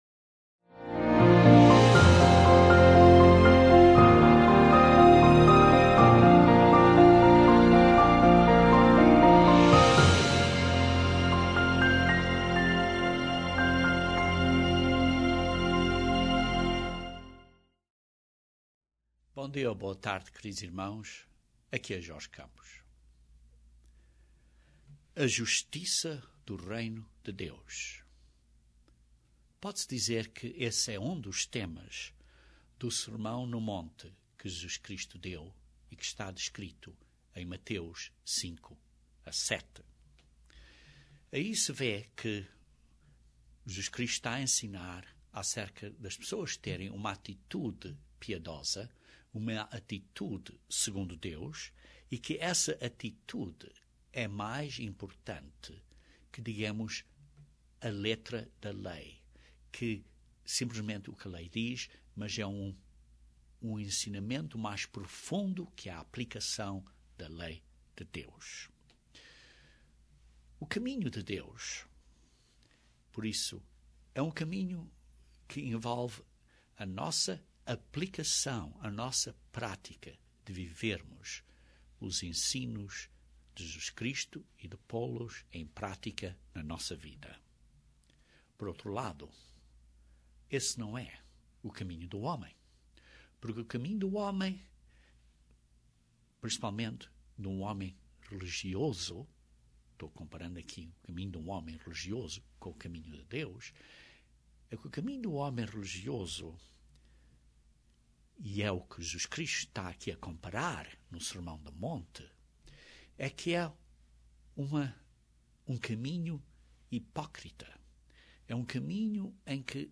Este sermão fala acerca de dois dos Seus ensinamentos. Como tratar outros e ter cuidado de não condenarmos nimguém. E como tratar aqueles que não desejam aceitar as Verdades de Deus.